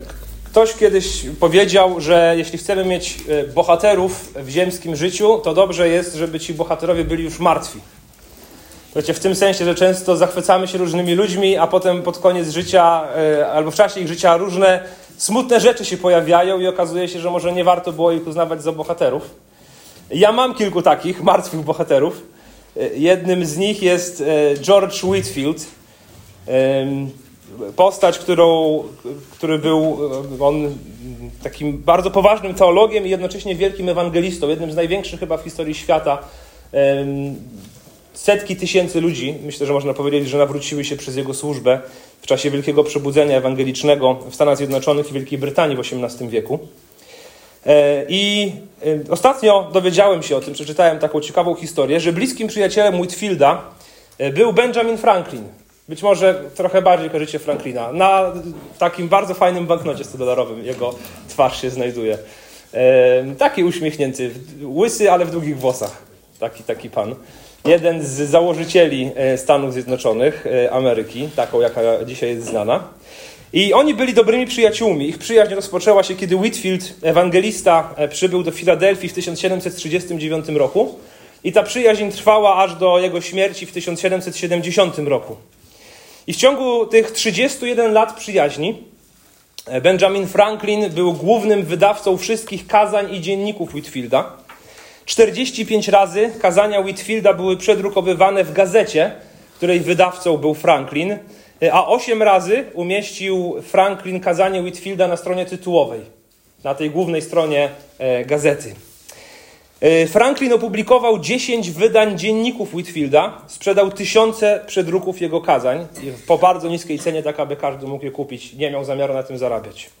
Kościół Chrześcijan Baptystów LOGOS w Bydgoszczy
To kazanie prowadzi nas przez jedno z najtrudniejszych podsumowań publicznej służby Jezusa, stawiając niewygodne pytania o niewiarę, zatwardziałość serca i wybór między chwałą Boga a chwałą ludzi.